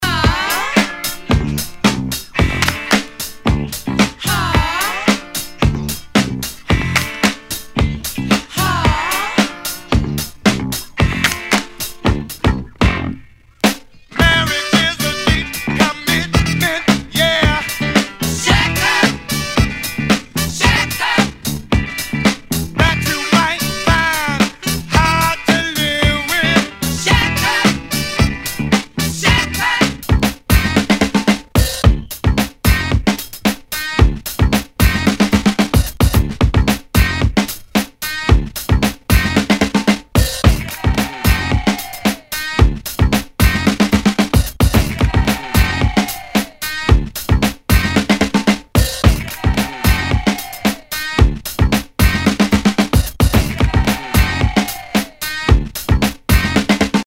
Nu- Jazz/BREAK BEATS